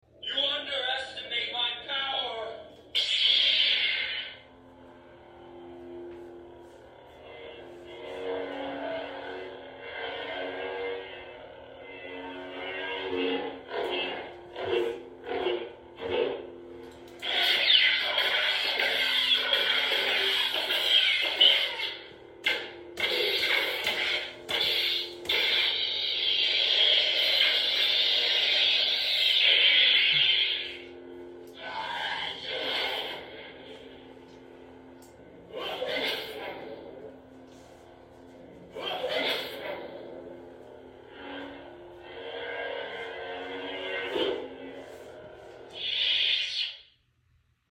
DARK ANI BK Saber sound effects free download